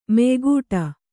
♪ meygūṭa